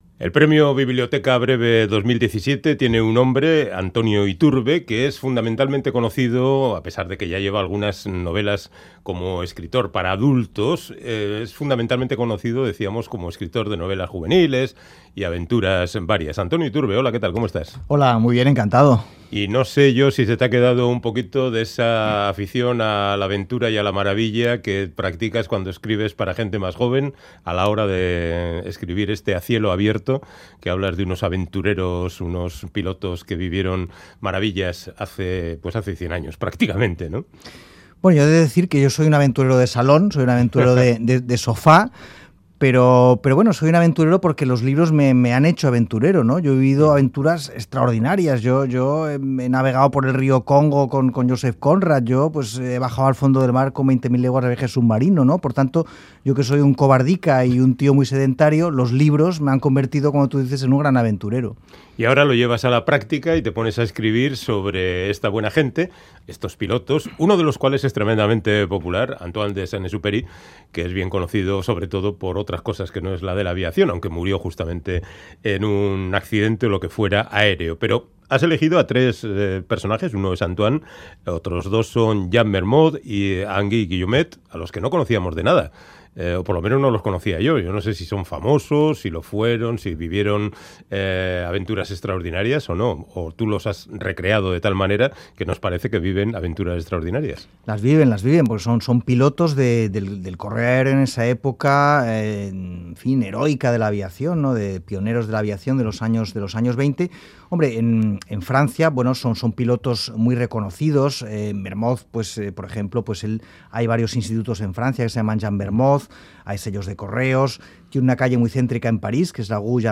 Charlamos con el aragonés Antonio Iturbe (Zaragoza, 1967), afincado en Barcelona , que acaba de publicar en Seix Barral la novela A cielo abierto , con la que ha ganado el Premio Biblioteca Breve